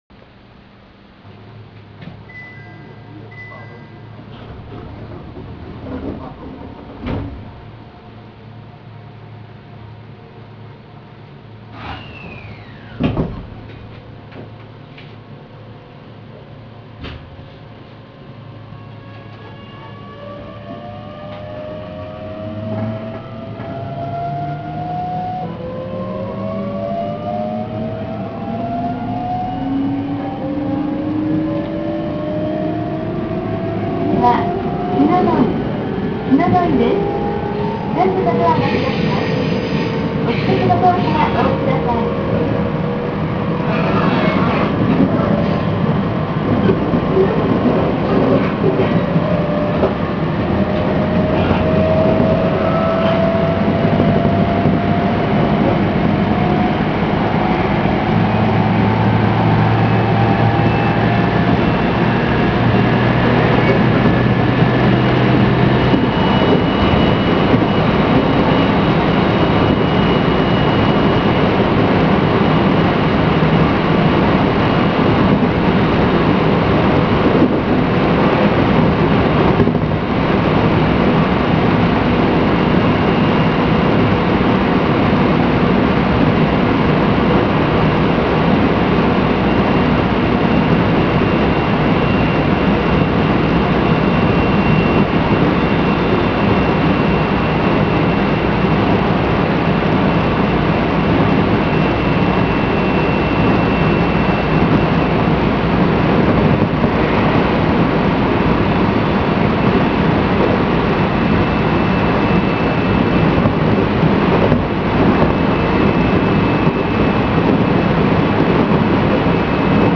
・E127系100番台走行音(東洋GTO)
【篠ノ井線】今井→篠ノ井（4分39秒：1.72MB）
私鉄に多い東洋GTOのモーター音。
0番台、100番台共に走行音は変わりません。